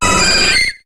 Cri de Leveinard dans Pokémon HOME.